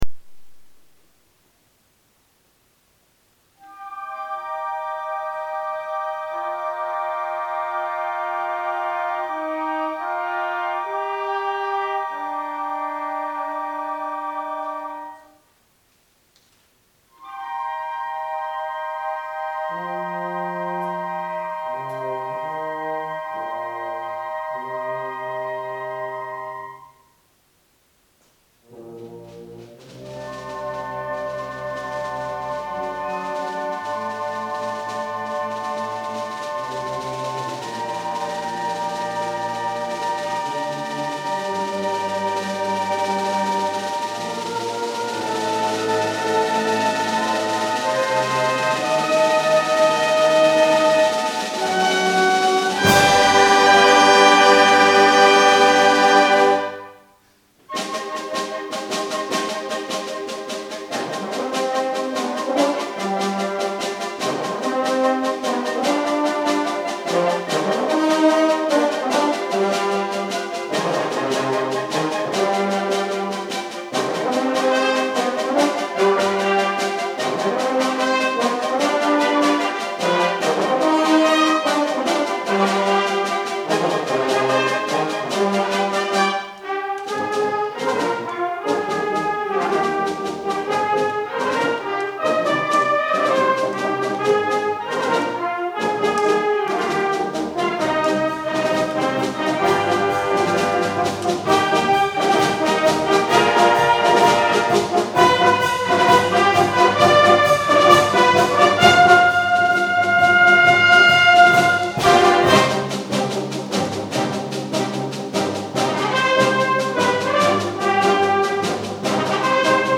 Aufnahme Konzertmusikbewertung 2013